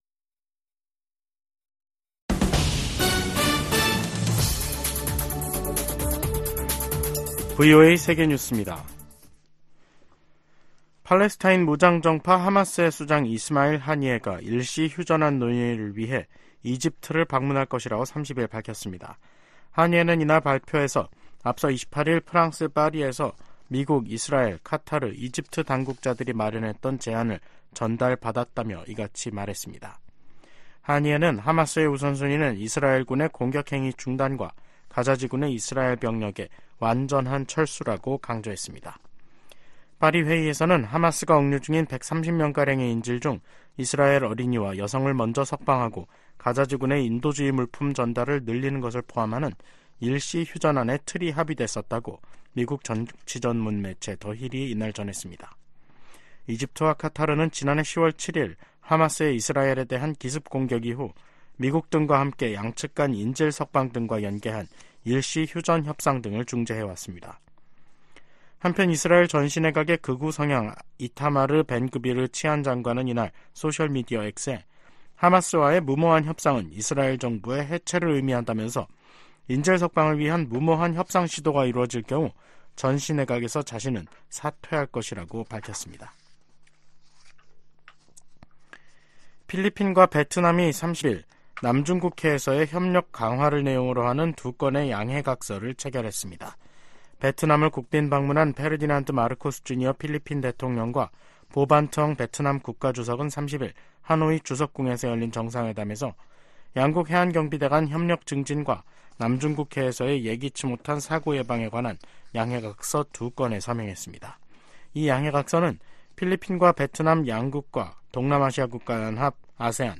VOA 한국어 간판 뉴스 프로그램 '뉴스 투데이', 2024년 1월 30일 3부 방송입니다. 북한이 이틀 만에 서해상으로 순항미사일 여러 발을 발사했습니다. 미국 국방부가 북한의 순항미사일 발사와 관련해 미한일 3국 협력의 중요성을 강조했습니다.